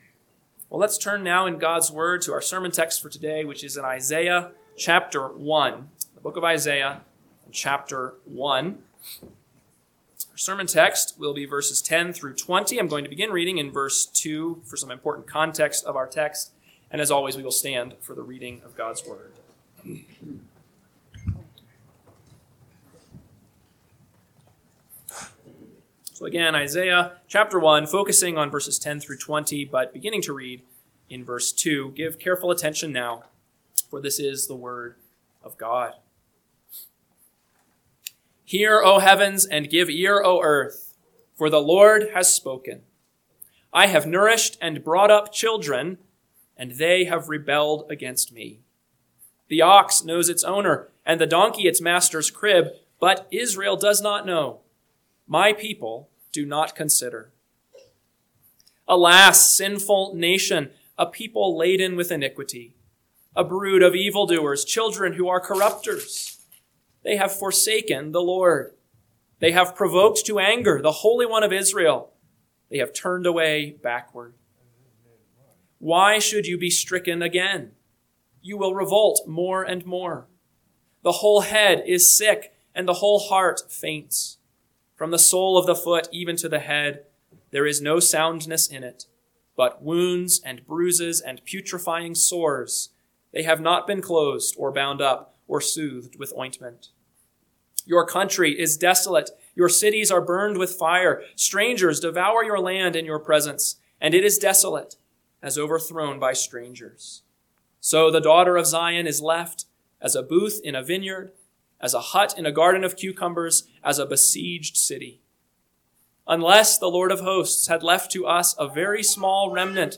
AM Sermon – 10/5/2025 – Isaiah 1:10-20 – Northwoods Sermons